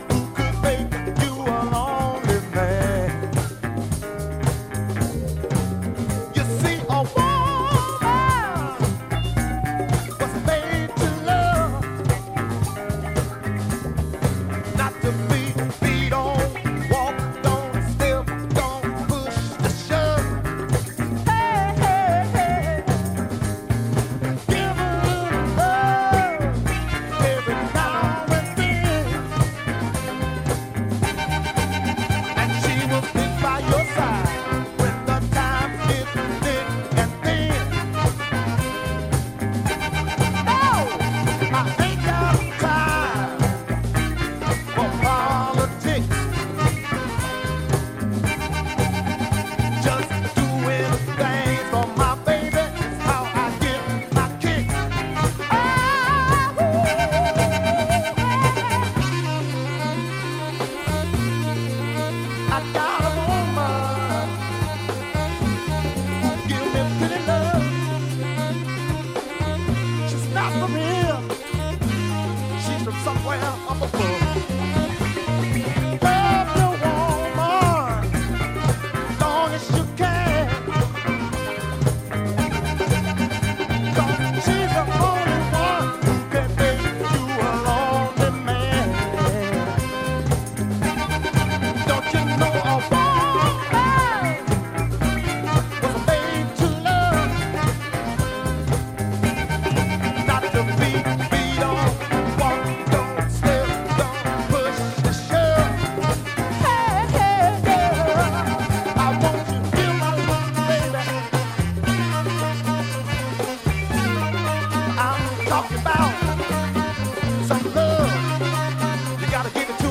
violinist and keysman
jazz-funk rarity
Soul Funk